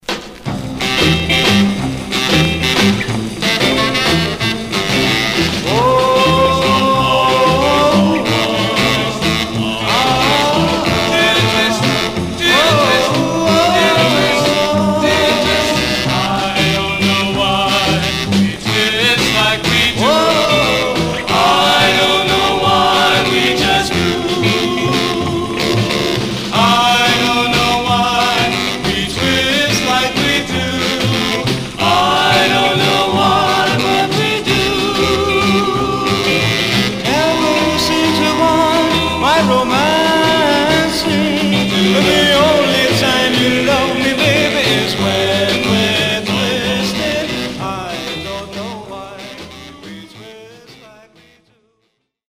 Condition Surface noise/wear Stereo/mono Mono